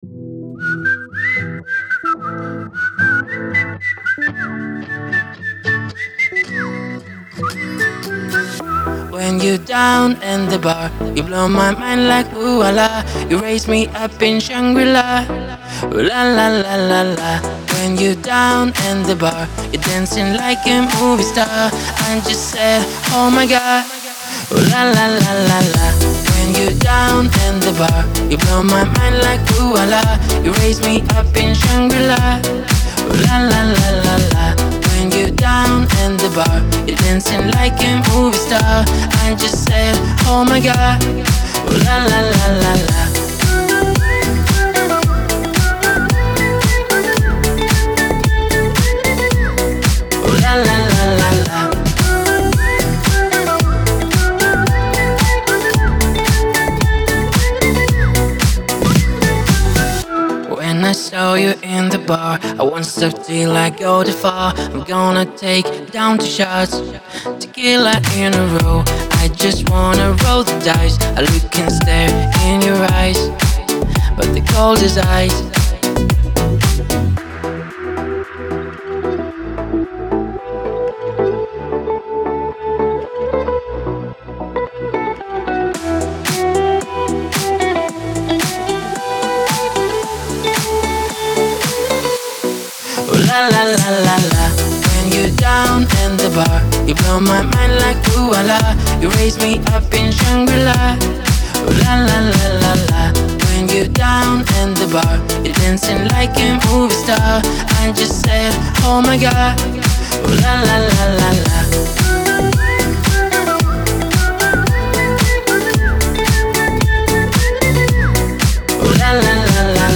Жанр: Pop, Dance